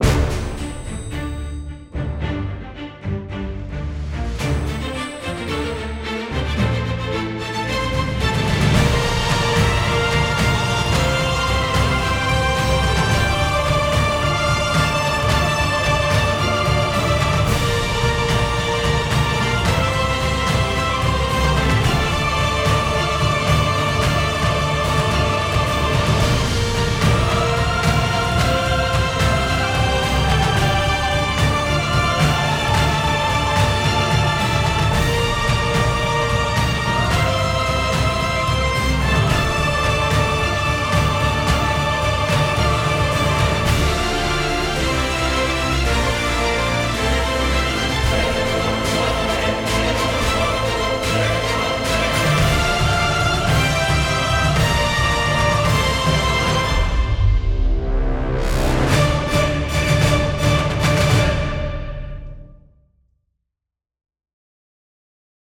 Epic